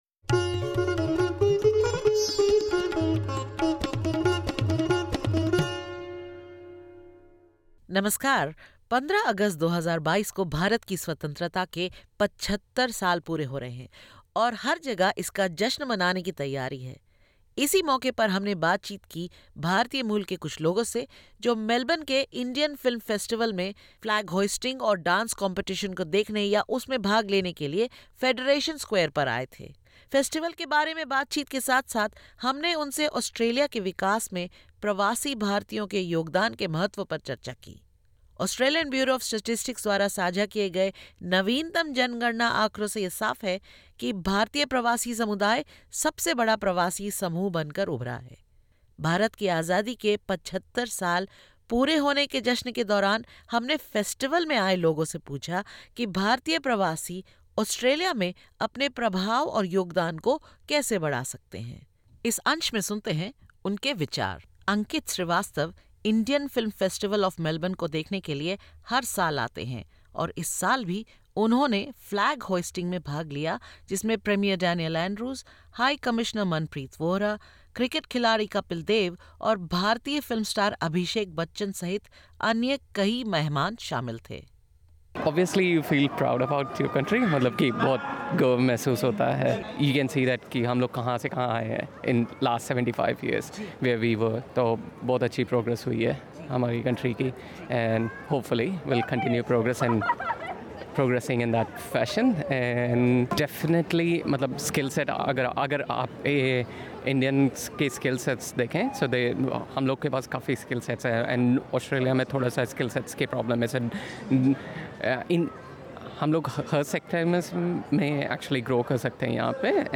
SBS Hindi spoke to some people who attended the Indian Film Festival of Melbourne's flag hoisting and IFFM Dance Competition at Federation Square about how Indian migrants can increase their contribution and influence in Australia.